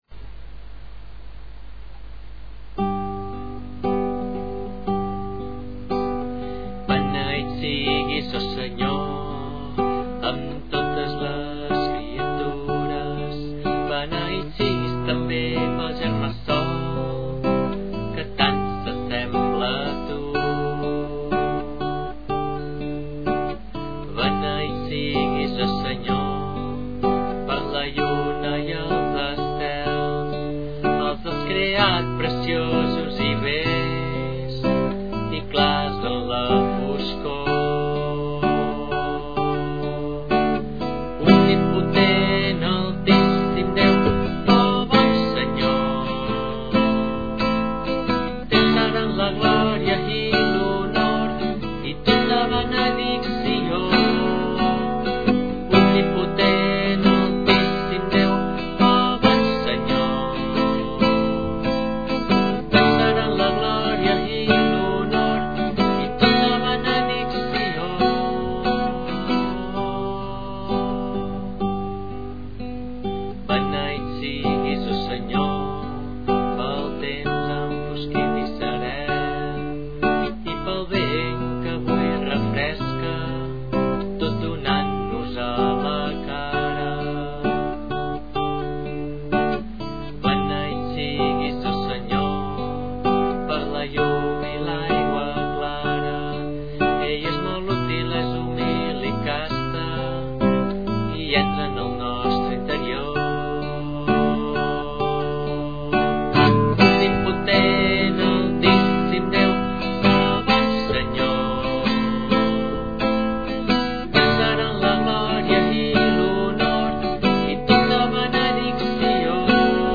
a la flauta travessera
guitarra i veu.
i formar el grup de guitarra i flauta del Convent d’Arenys.